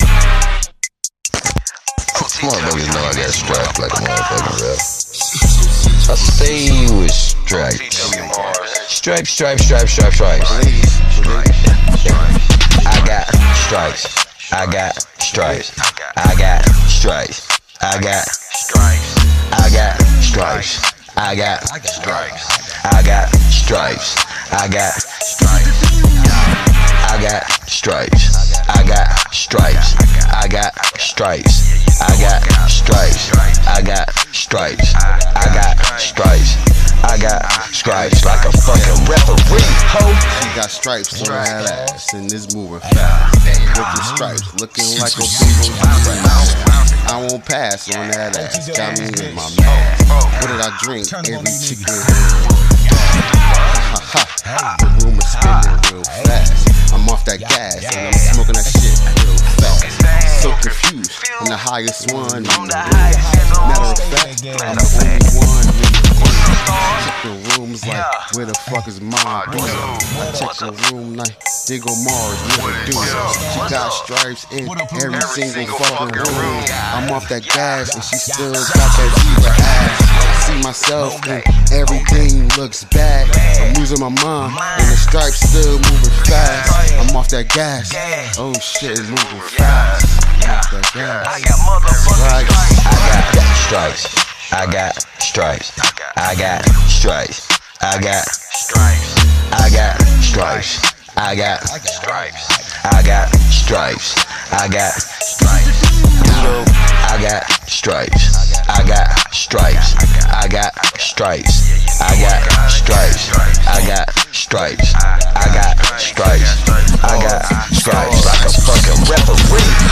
Quality of sound may not be 💯 but respect the grind.